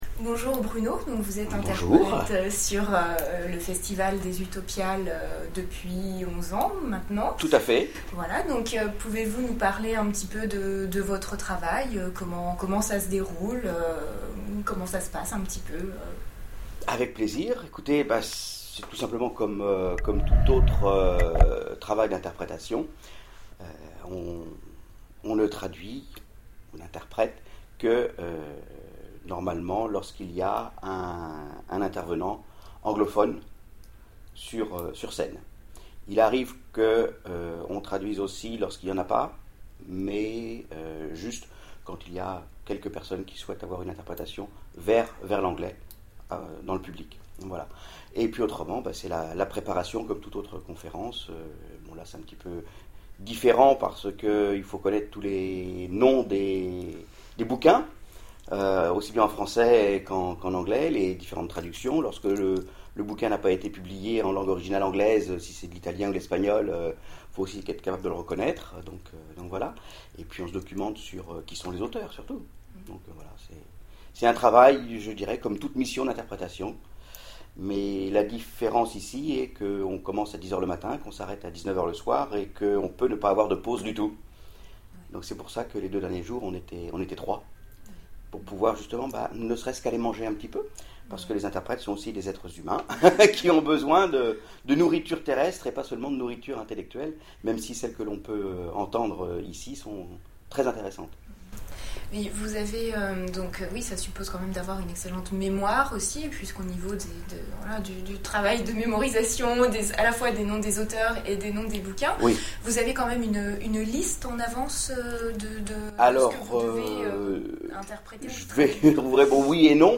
Utopiales 2011 : Interview